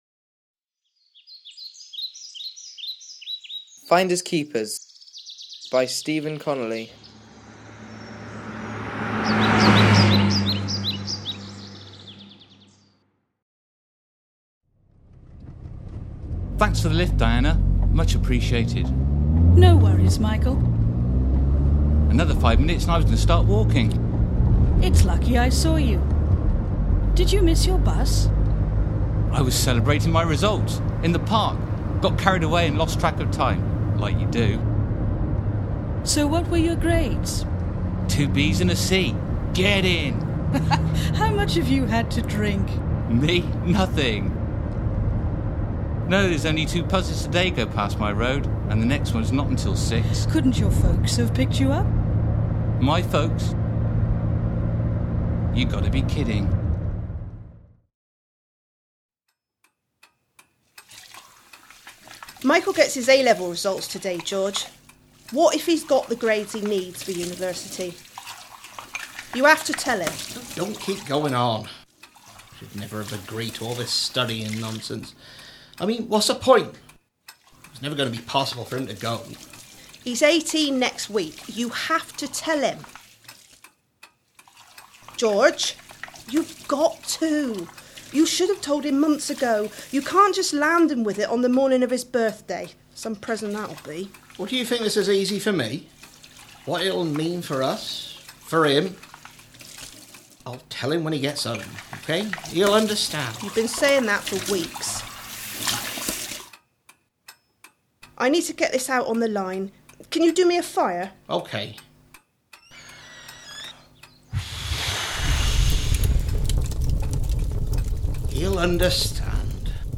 The script wasn’t accepted, but I have always liked the idea so last year I decided to rework and record it for Cirencester’s own community radio station Corinium Radio.